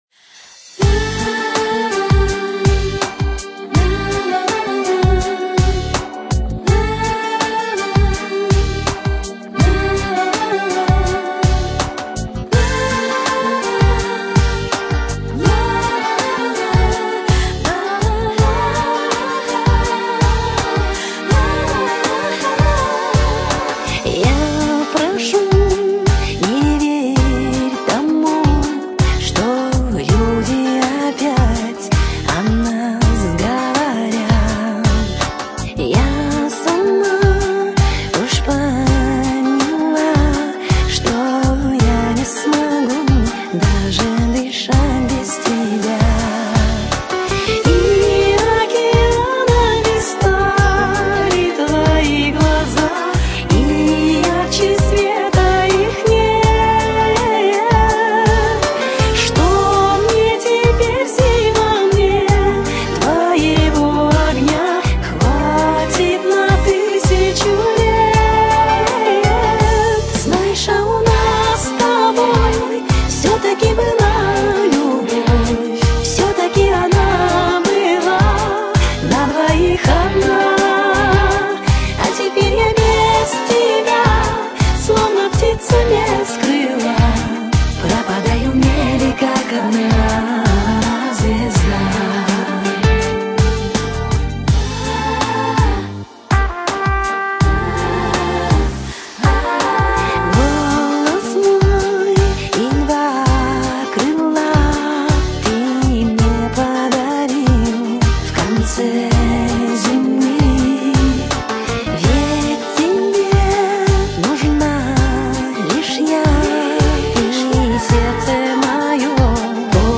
музыка шансон